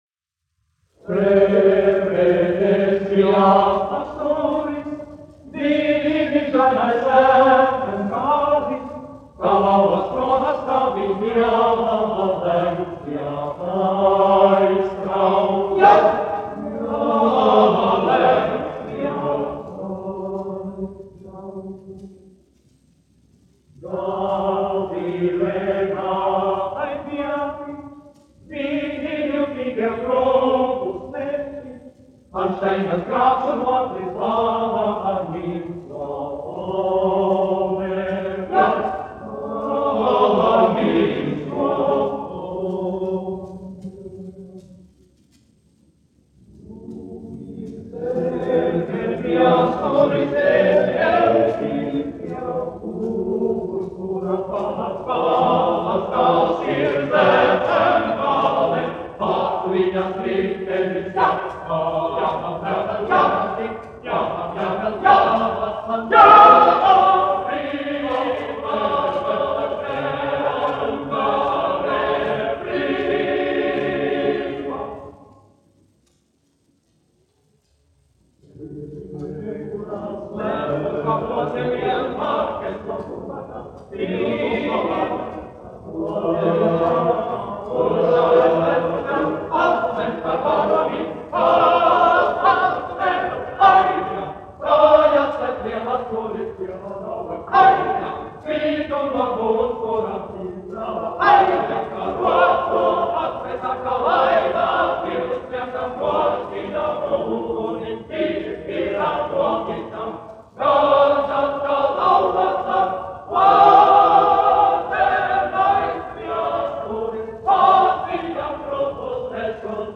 Zuikas vīru koris, izpildītājs
1 skpl. : analogs, 78 apgr/min, mono ; 25 cm
Kori (vīru)
Skaņuplate
Latvijas vēsturiskie šellaka skaņuplašu ieraksti (Kolekcija)